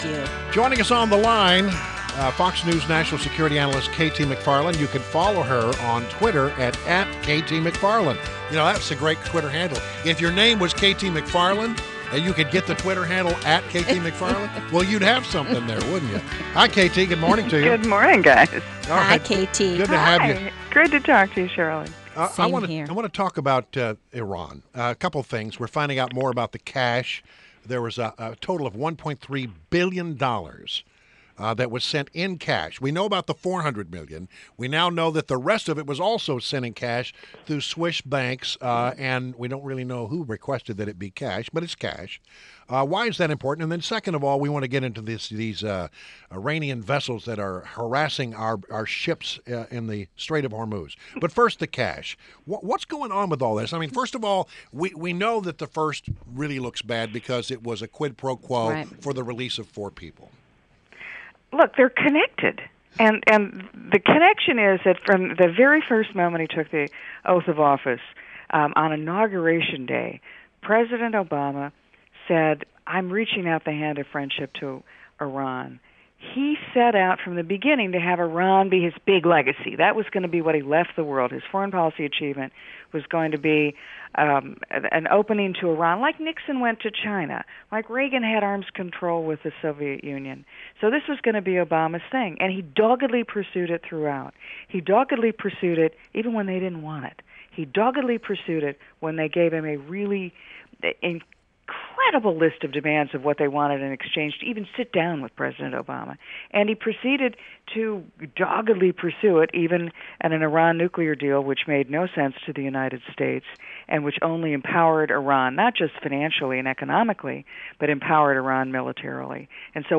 WMAL Interview - KT MCFARLAND - 09.07.16